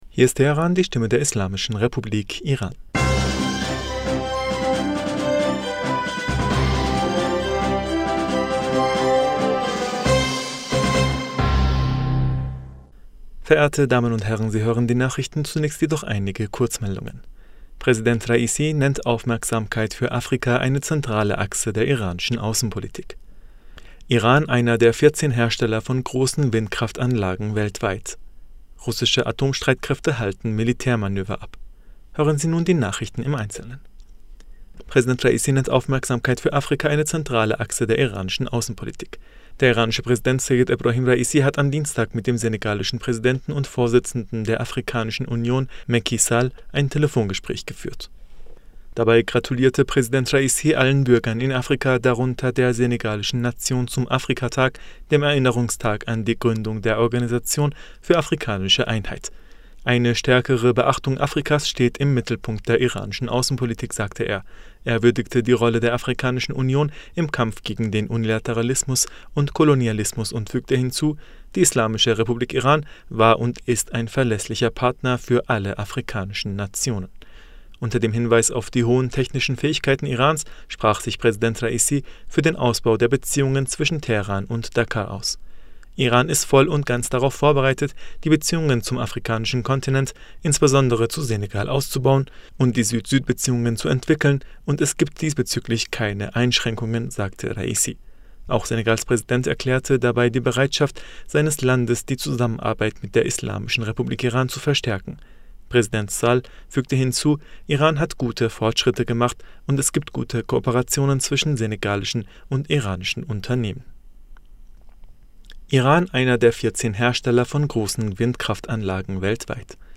Nachrichten vom 1. Juni 2022